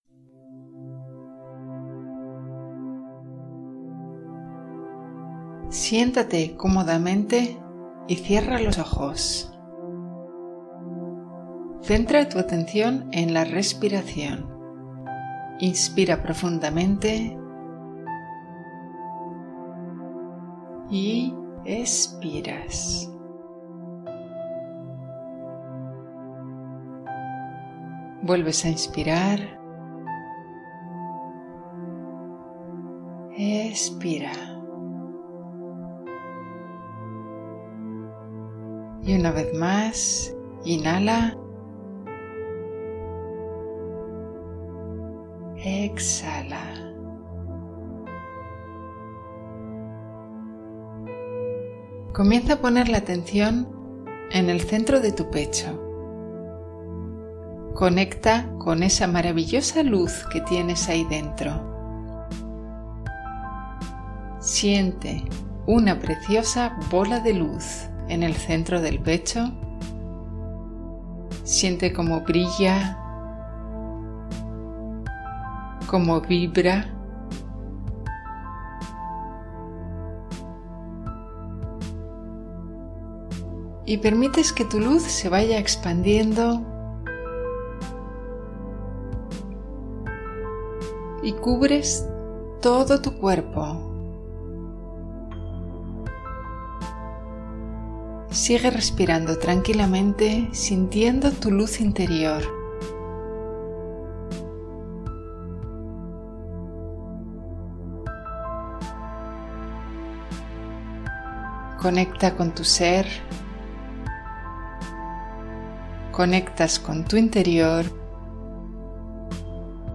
Meditación  para reconectar con tu esencia en 3 minutos